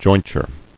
[join·ture]